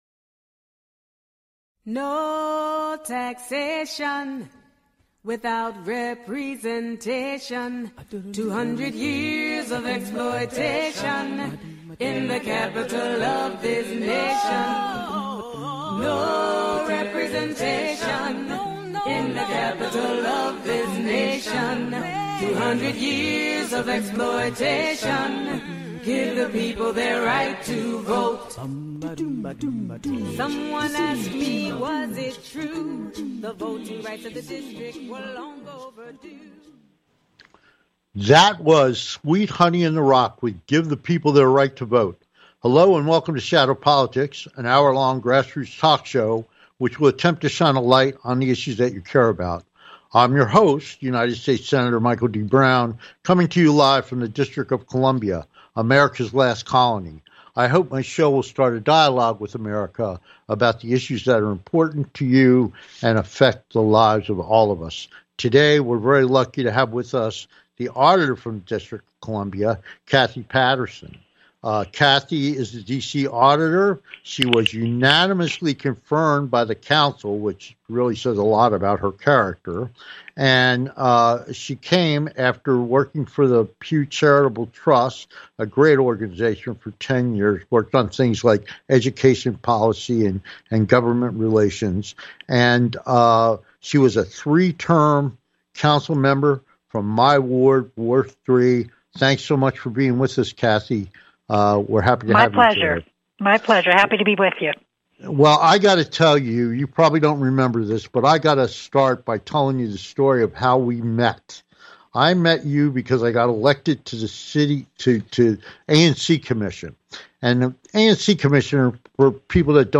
Keeping an Eye on our Government - With Guest, Former Councilwoman and DC Auditor, Kathy Patterson